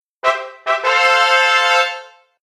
trumpet_announcement.ogg